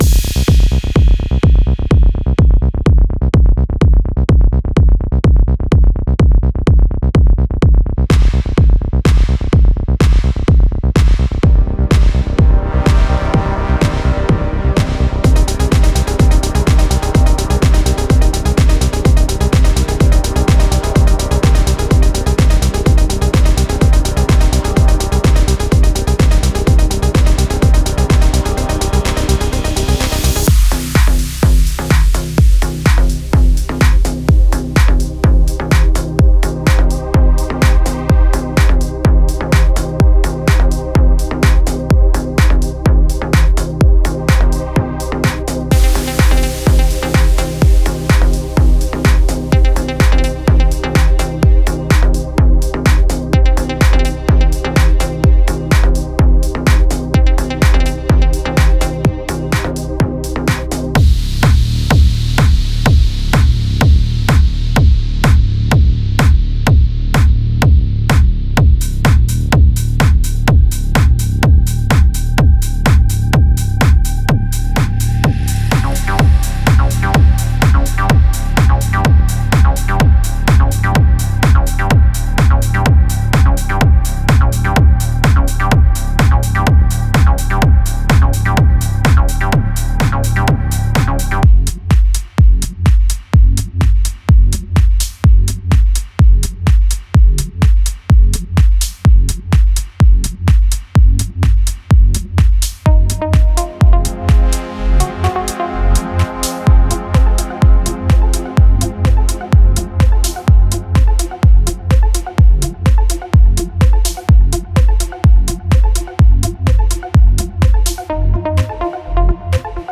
House Melodic Techno Minimal Tech House Techno